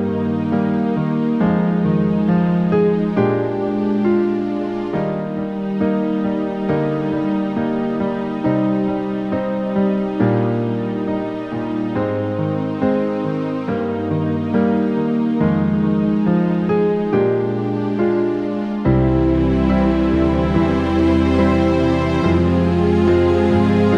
One Semitone Down Easy Listening 3:05 Buy £1.50